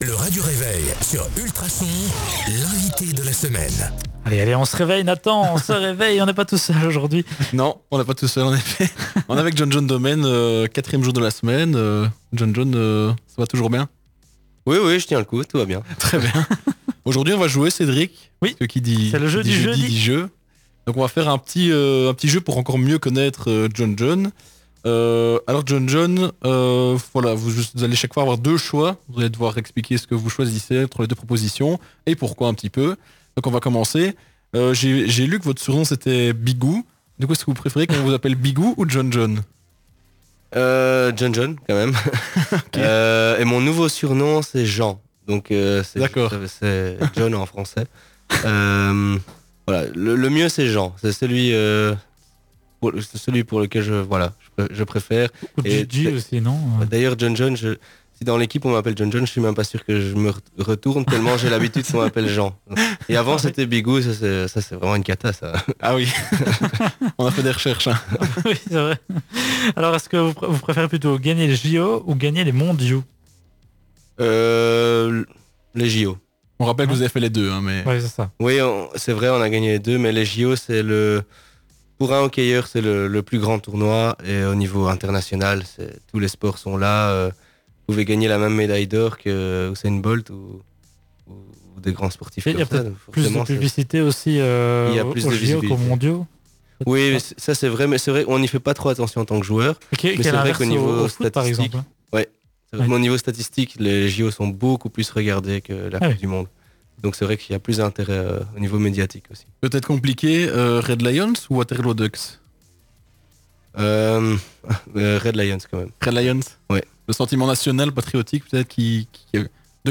4. L'invité de la semaine - John-John Dohmen
Ce jeudi 20 janvier dans le Radio Réveil, nous accueillons John-John Dohmen, ancien capitaine de l'équipe des Red Lions. Au travers du "Jeudi-jeux", il retrace avec nous son parcours en tant que joueur de Hockey; ses plus grands défis et ses plus belles victoires.